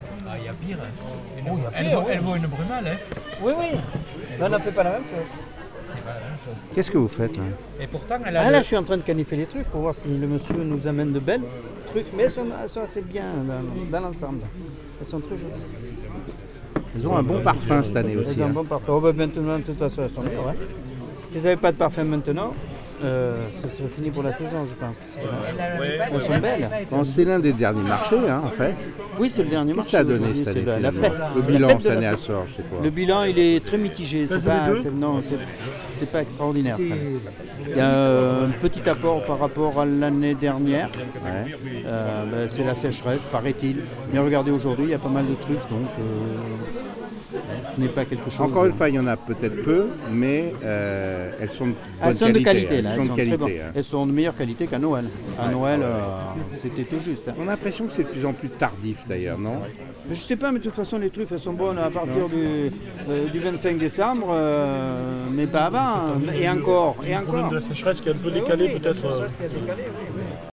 Reportage au cœur du Marché aux Truffes
reportage-ambiance-marché-truffe-sorges-2016.mp3